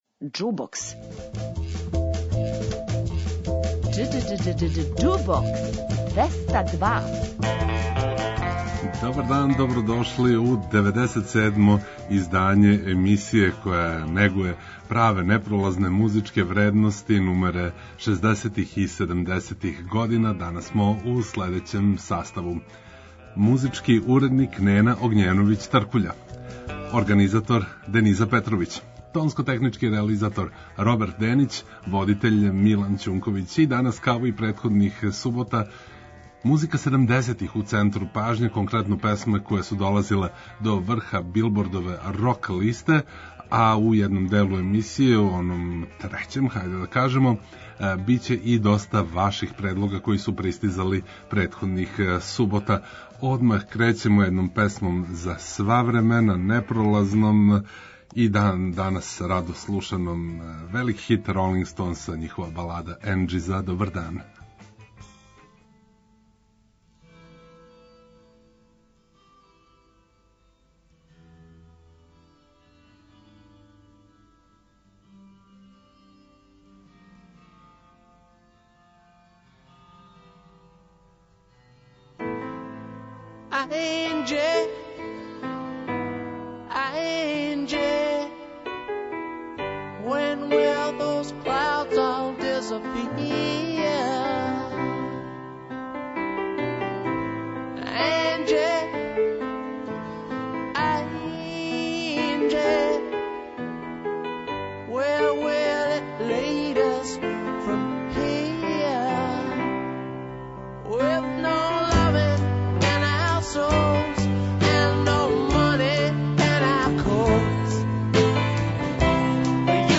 Хитови са Билбордове рок листе седамдесетих